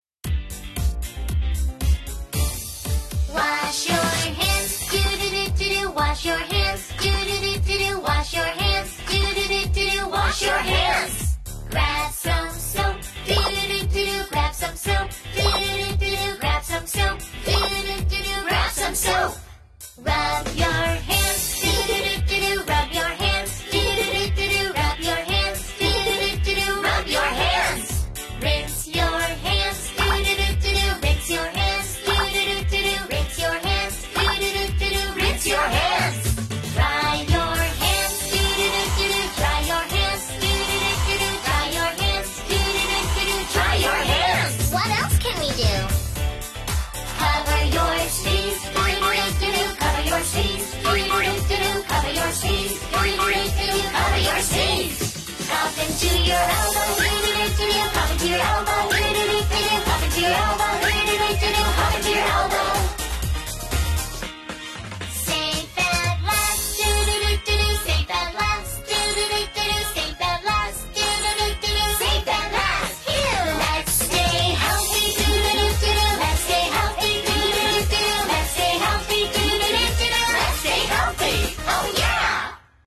A song to help your kids wash hands